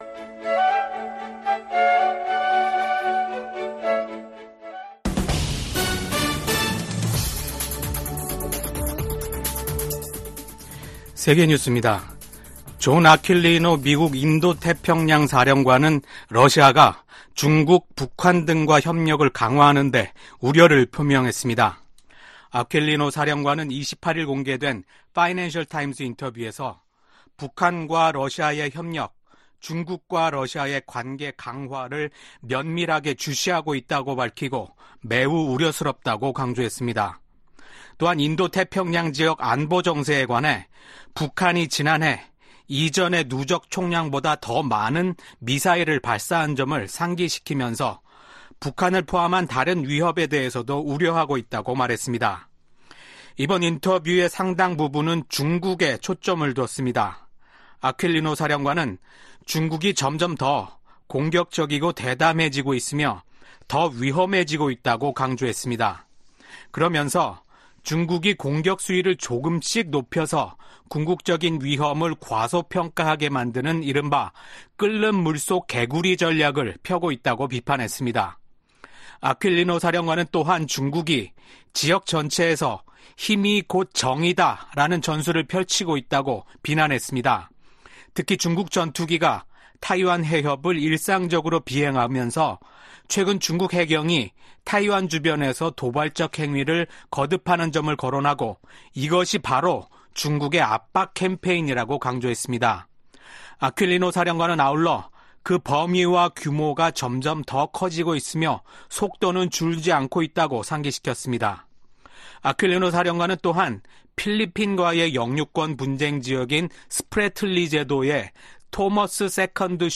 VOA 한국어 아침 뉴스 프로그램 '워싱턴 뉴스 광장' 2024년 4월 30일 방송입니다. 미국과 한국, 일본이 제14차 안보회의를 열고 지속적인 3국간 안보협력 의지를 재확인했습니다. 유엔 주재 미국 부대사는 중국과 러시아의 반대로 북한의 핵 프로그램에 대한 조사가 제대로 이뤄지지 못했다고 지적했습니다. 북한이 김정은 국무위원장이 참관한 가운데 신형 240mm 방사포탄 검수사격을 실시했습니다.